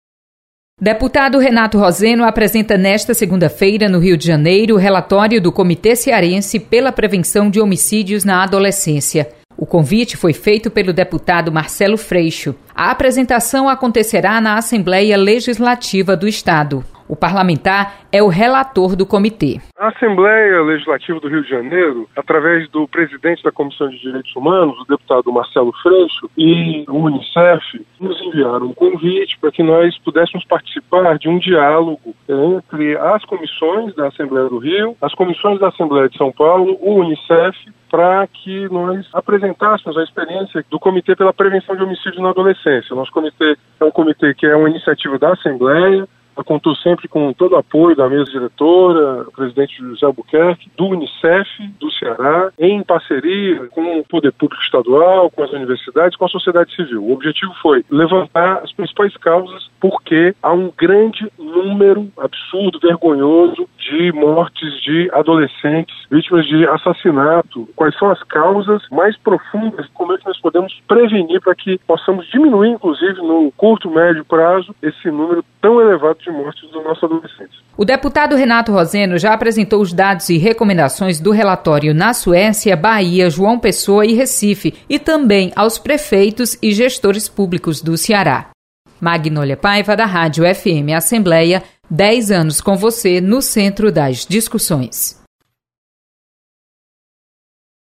Deputado Renato roseno apresenta relatório do Comitê em defesa dos adolescentes no Rio de Janeiro.